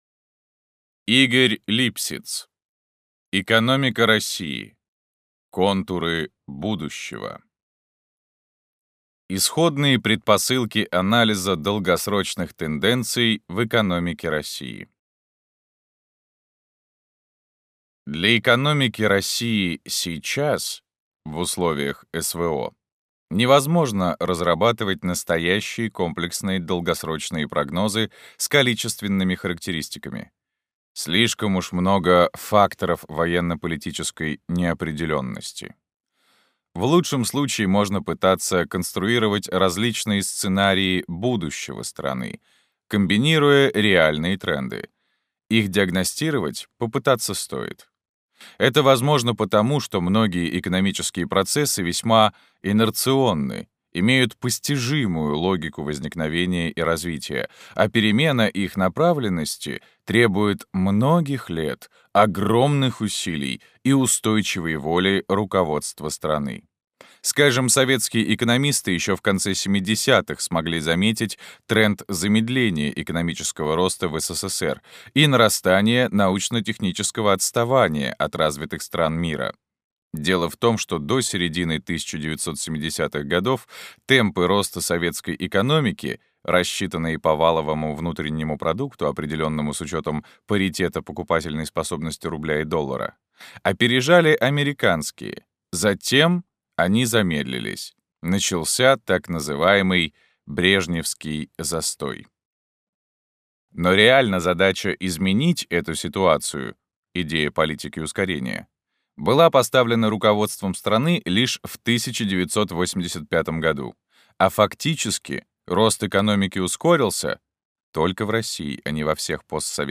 Аудиокнига Экономика России: контуры будущего | Библиотека аудиокниг